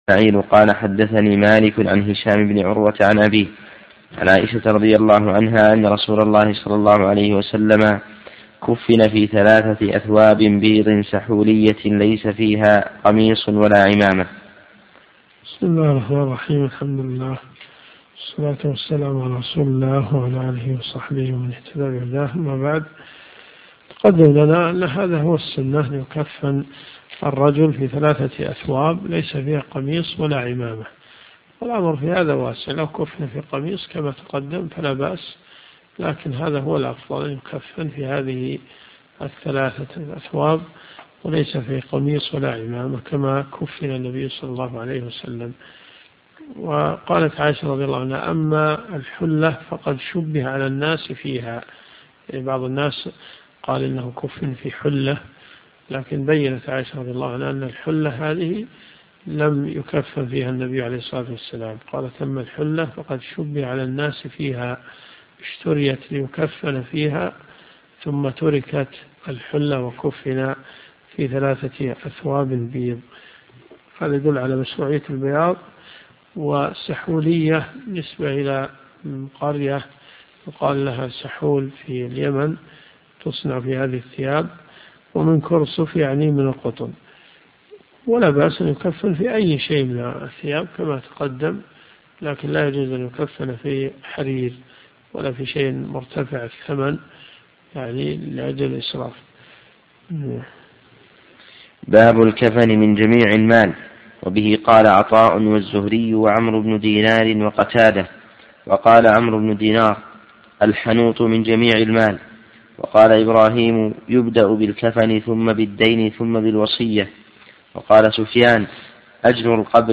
الرئيسية الكتب المسموعة [ قسم الحديث ] > صحيح البخاري .